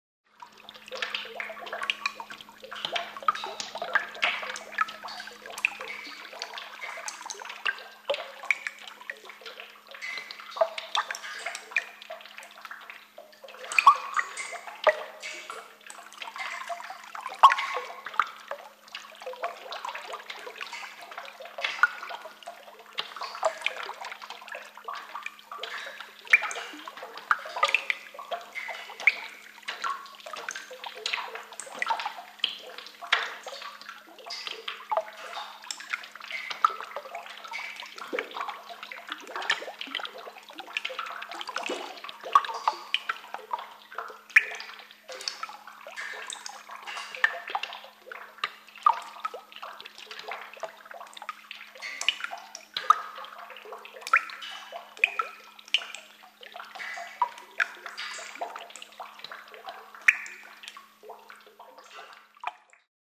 Звук капающей воды в пещере